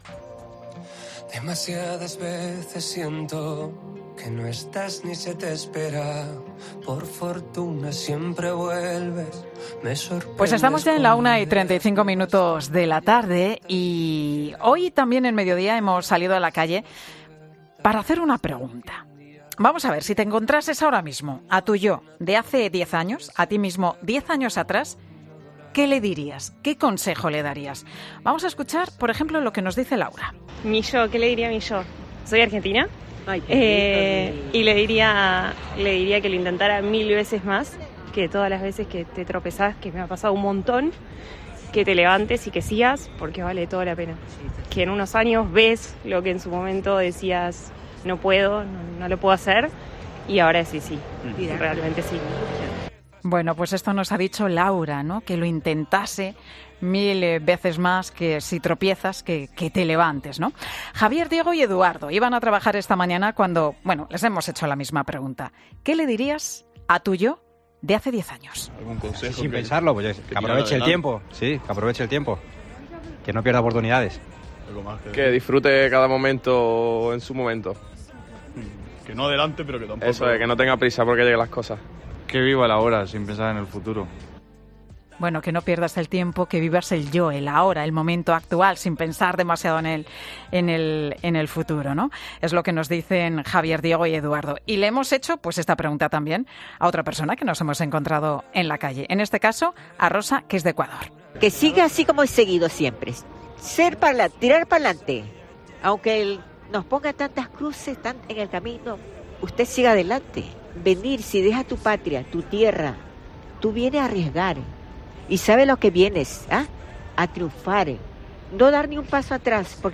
Hoy en Mediodía COPE hemos salido a la calle para hacer una pregunta. Si te encontraras ahora mismo a tu “yo” de hace diez años. ¿Qué consejo le darías?